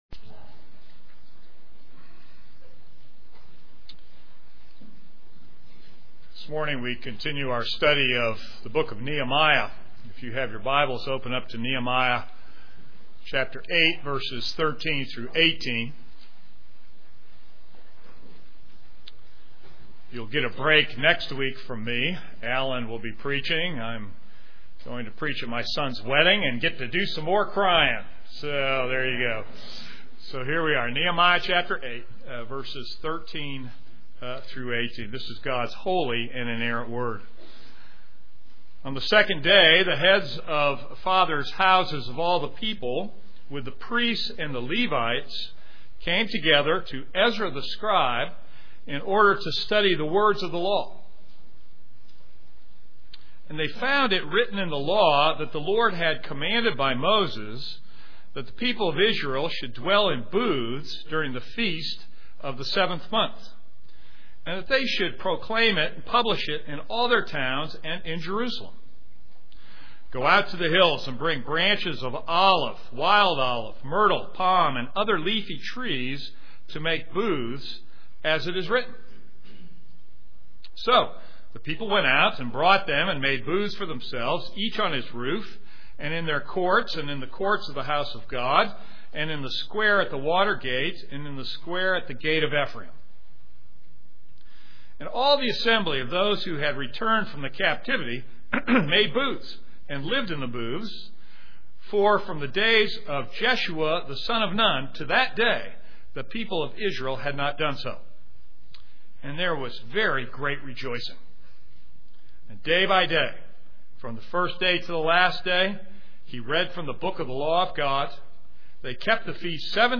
This is a sermon on Nehemiah 8:13-18.